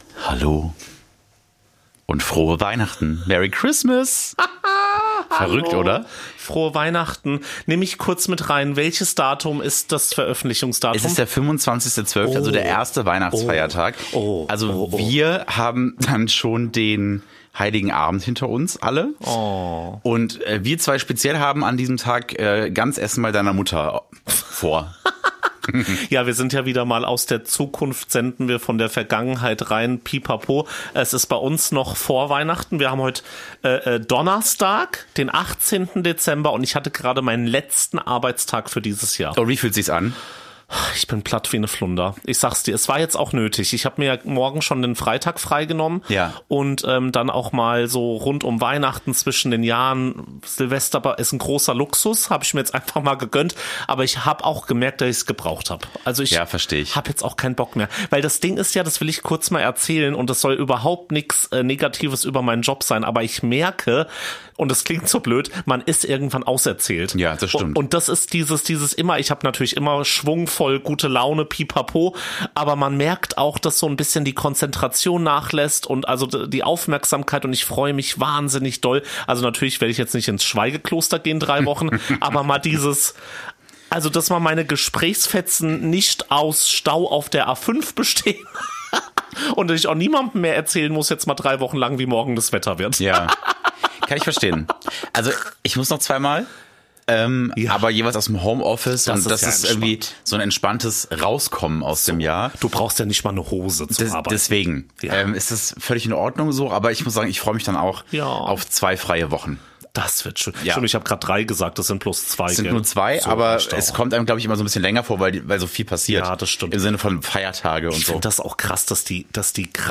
Wie versprochen, haben wir uns quer durch Deutschland telefoniert, um uns mit euch in Weihnachtsstimmung zu bringen und haben dabei schnell noch Tipps zum Wandern und für gute Fake-Weihnachtsbäume eingesammelt.